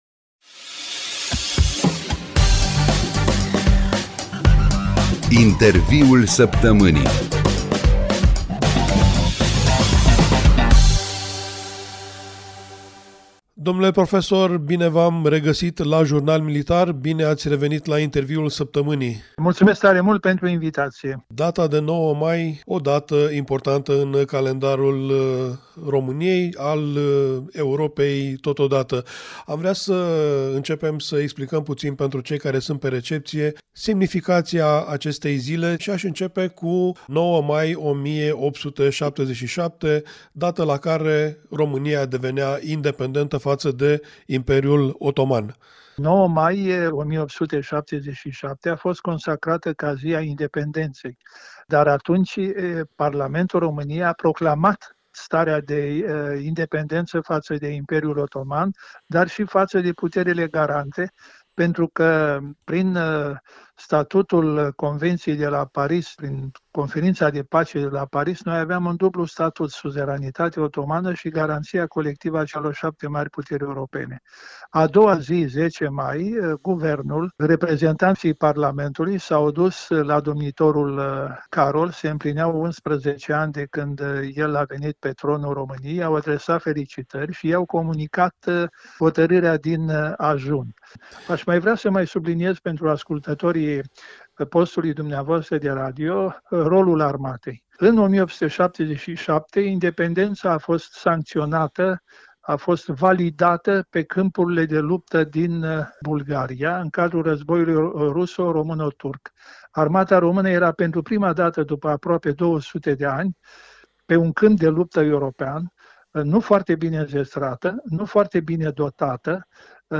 Interviul săptămânii